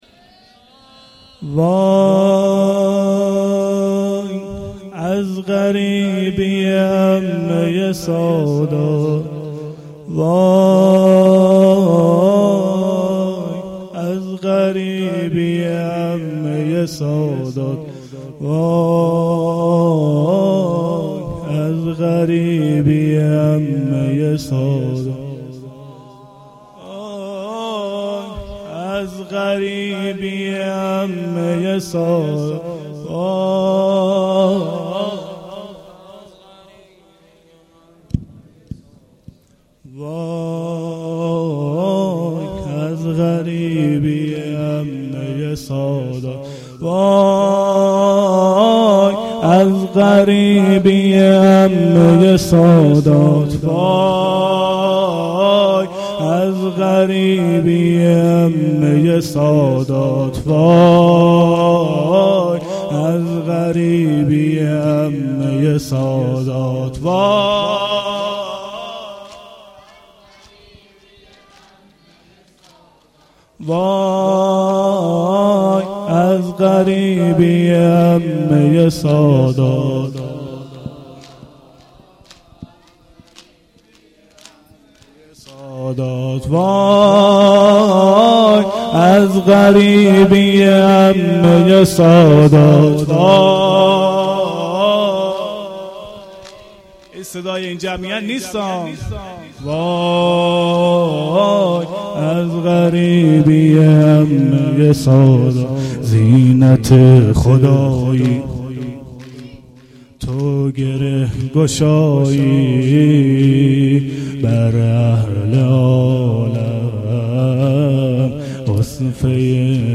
گزارش صوتی جلسه هفتگی 28 محرم الحرام